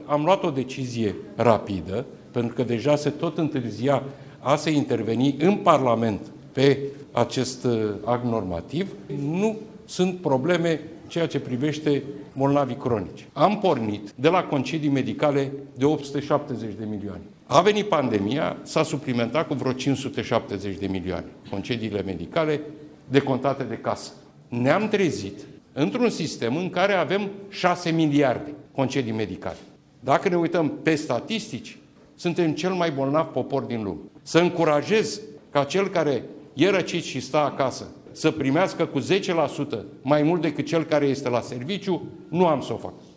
Așa a declarat, astăzi, premierul Marcel Ciolacu.
Declaraţia a fost făcută, astăzi, la Institutul Clinic Fundeni, unde premierul a anunţat că aici se va construi un nou ansamblu medical, care va include 5 cladiri spitaliceşti de 6 etaje, plus grădini terapeutice.